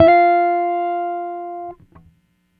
Guitar Slid Octave 18-F3.wav